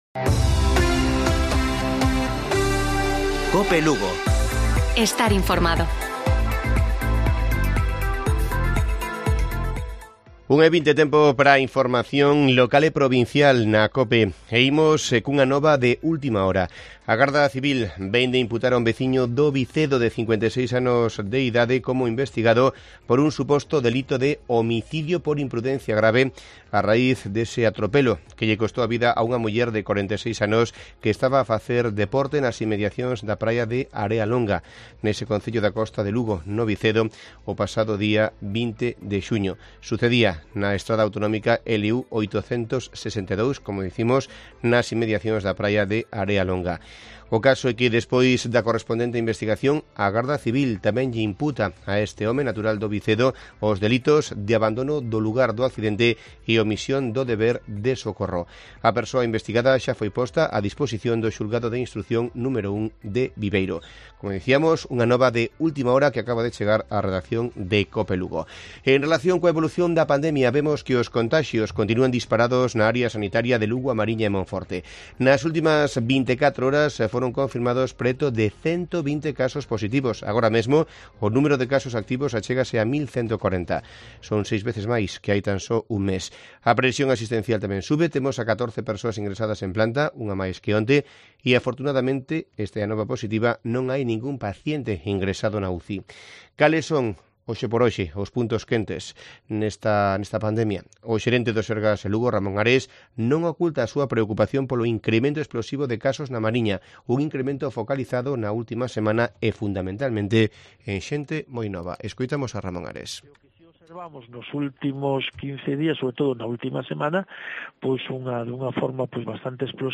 Informativo Provincial de Cope Lugo. 20 de julio. 13:20 horas